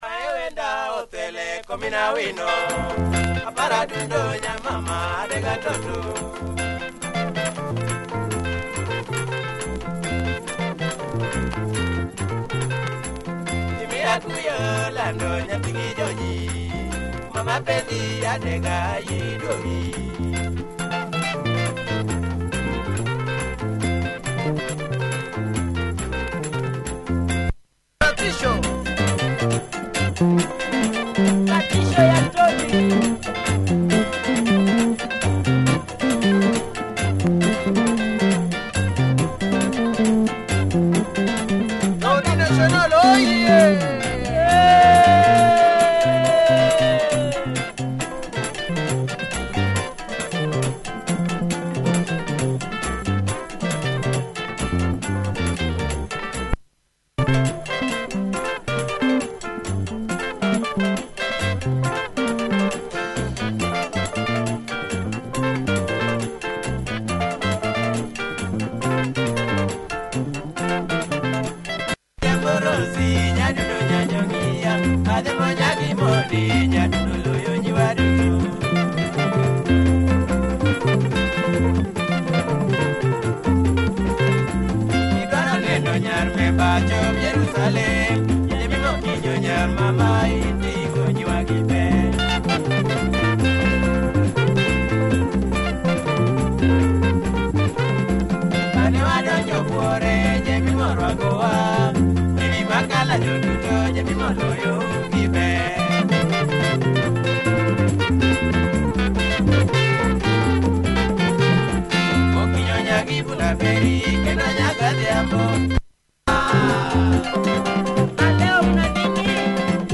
Nice luo benga by this famous group. https